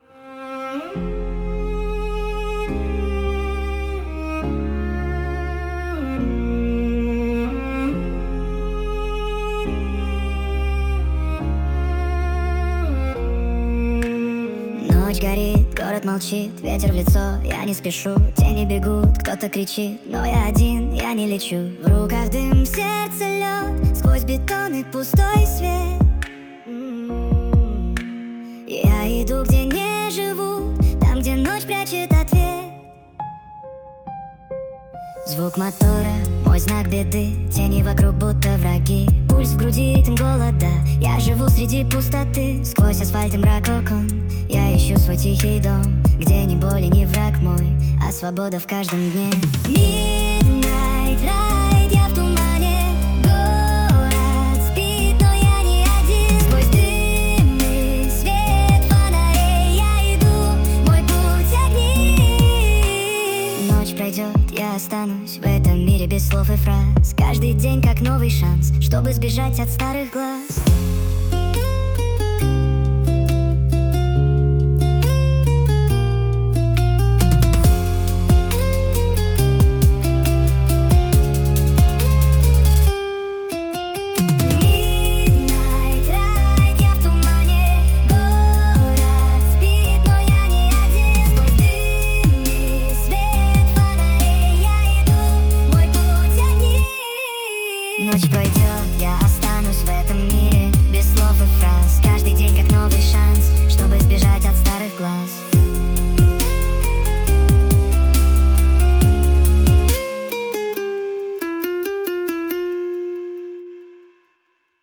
Жанр: CRIMEWAVE